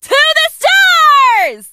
janet_ulti_vo_05.ogg